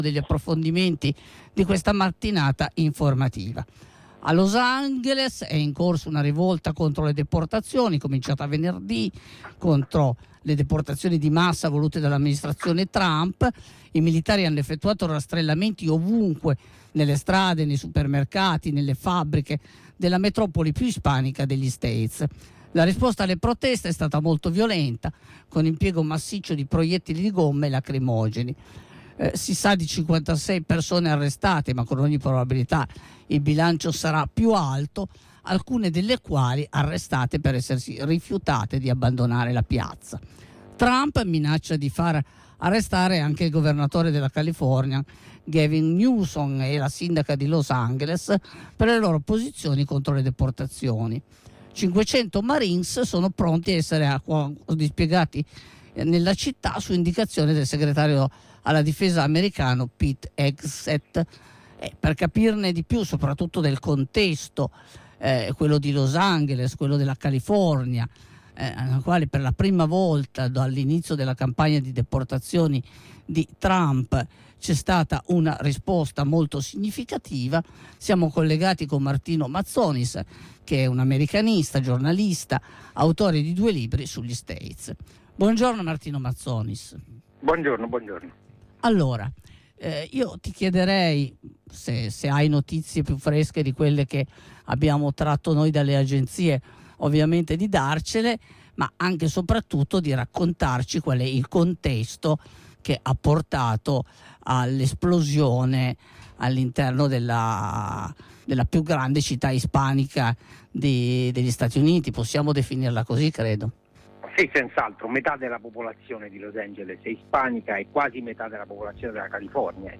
giornalista, americanista ed autore di due libri sugli States